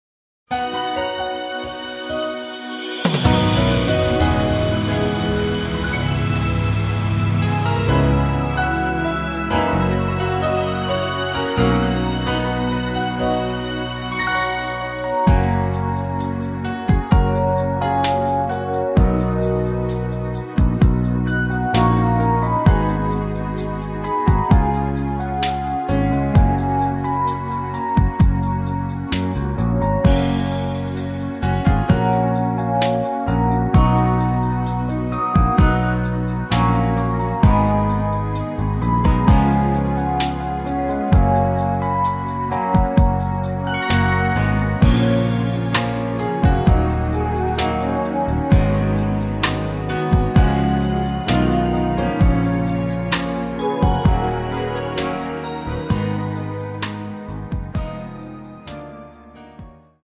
F#
◈ 곡명 옆 (-1)은 반음 내림, (+1)은 반음 올림 입니다.
앞부분30초, 뒷부분30초씩 편집해서 올려 드리고 있습니다.
중간에 음이 끈어지고 다시 나오는 이유는